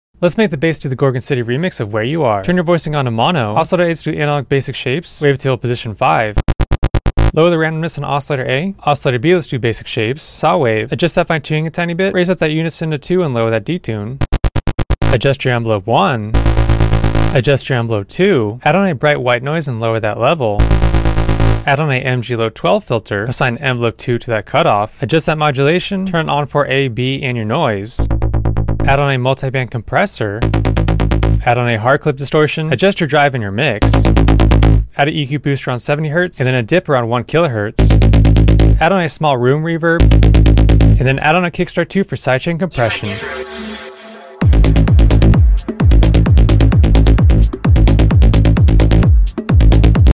synth, sound design